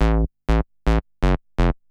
SNTHBASS001_PROGR_125_A_SC3.wav
1 channel